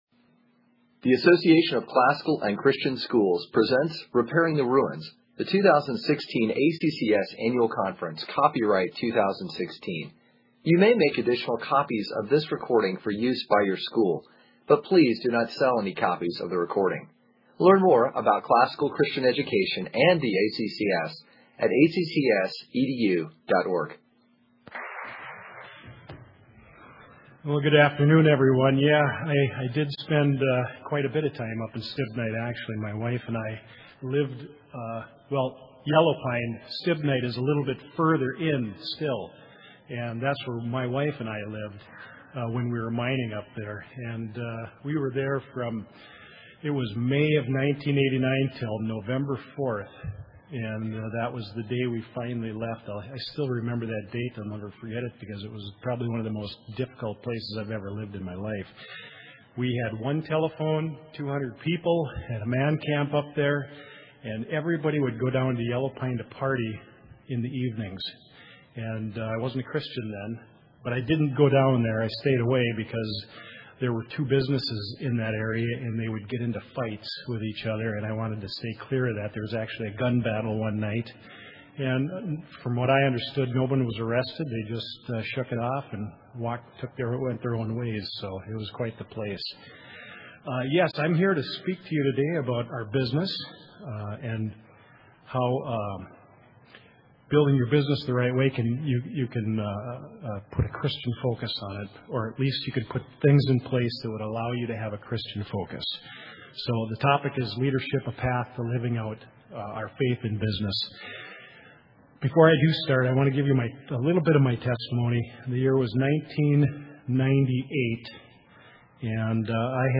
2016 Leaders Day Talk | 41:09:00 | Culture & Faith